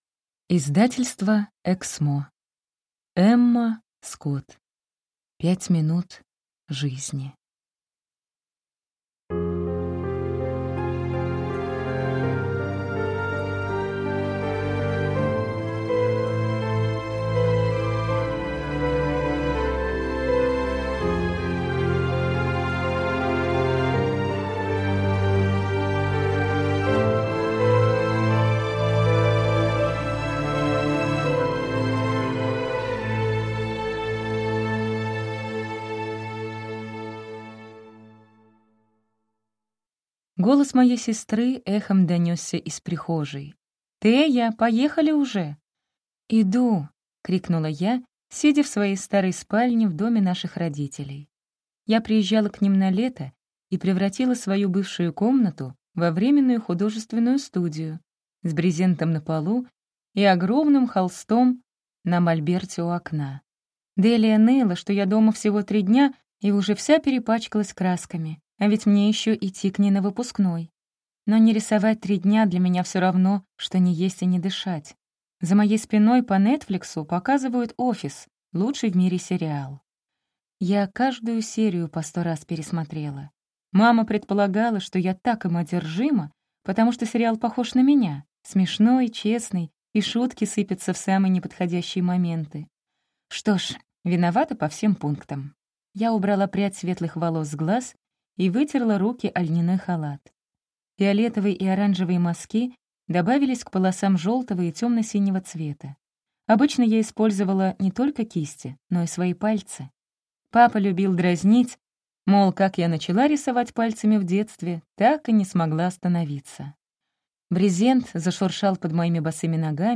Аудиокнига Пять минут жизни | Библиотека аудиокниг